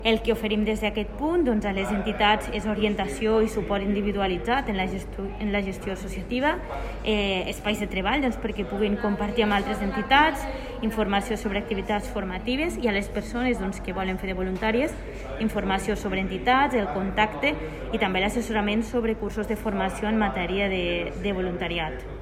tall-de-veu-de-la-regidora-de-participacio-ciutadana-elena-ferre